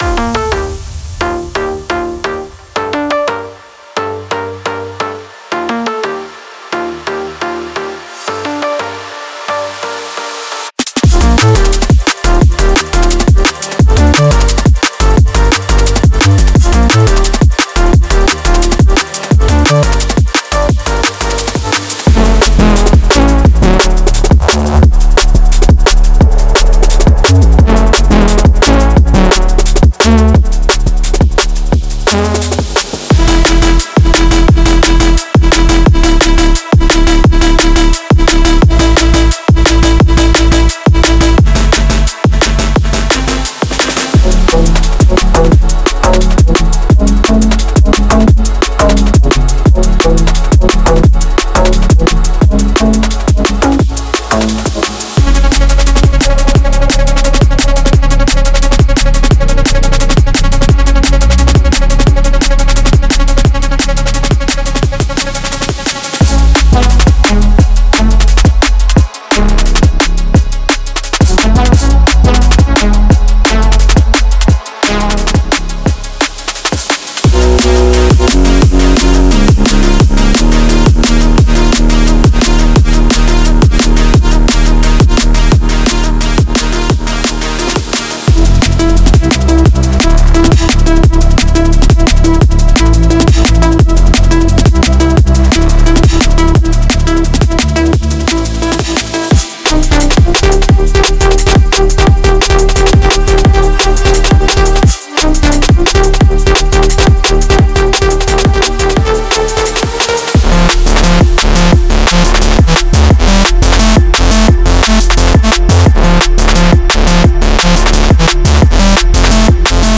Genre:Liquid
エーテルのようなヴァイブス。
深く刻むリズム。
表情豊かなエネルギー。
温かみのあるパッド、滑らかなベースライン、そしてオーガニックなパーカッション
デモサウンドはコチラ↓